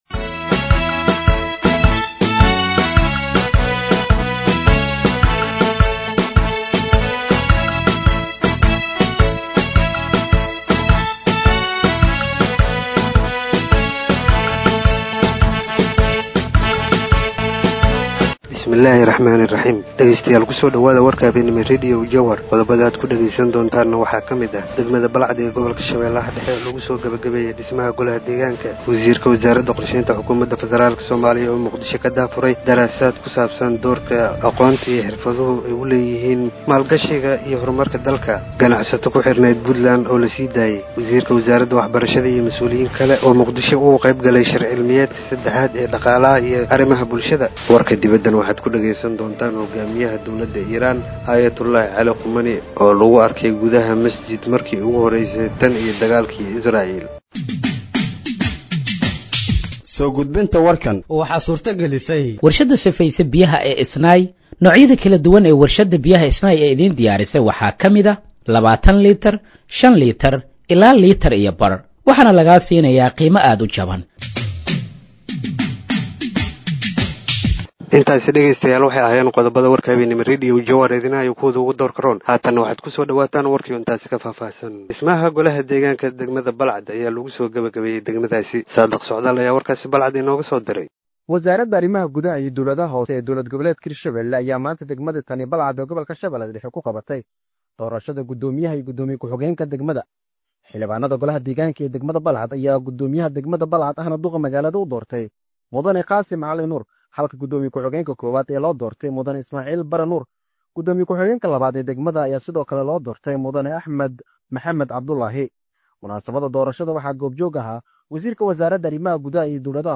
Dhageeyso Warka Habeenimo ee Radiojowhar 06/07/2025
Halkaan Hoose ka Dhageeyso Warka Habeenimo ee Radiojowhar